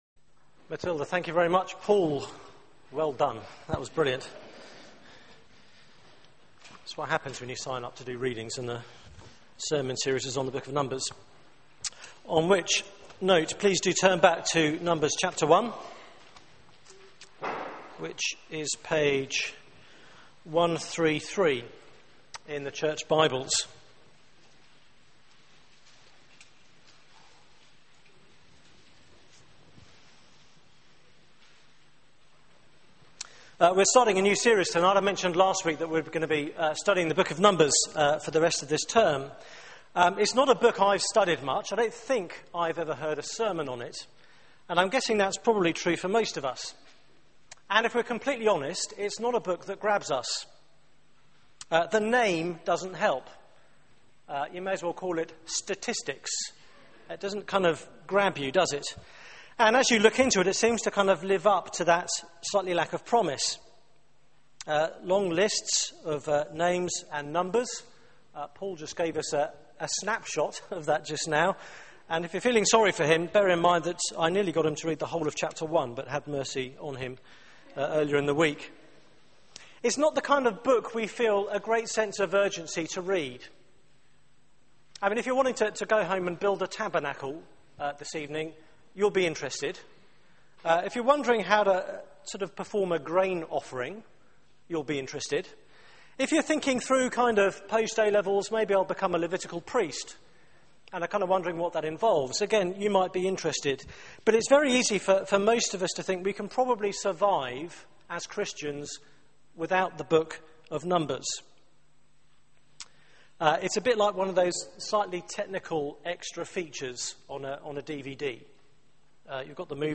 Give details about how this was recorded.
Media for 6:30pm Service on Sun 27th May 2012